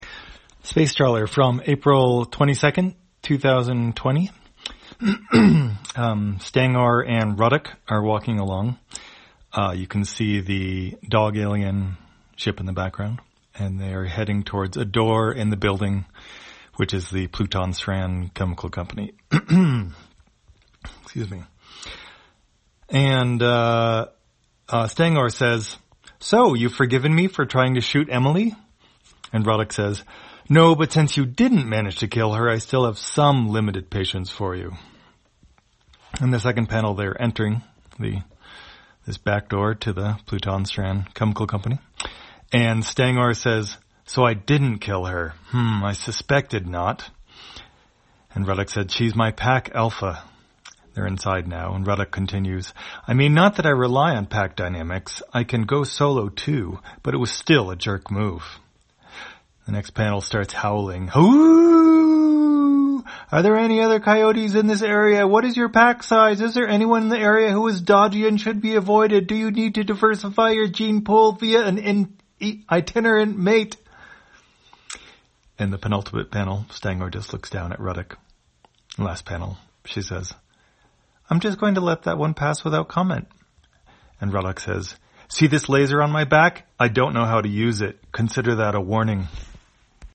Spacetrawler, audio version For the blind or visually impaired, April 22, 2020.